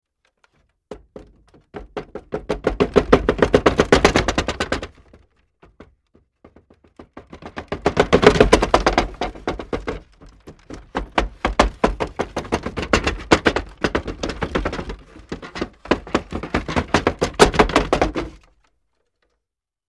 Звук кота, который стучит лапами по двери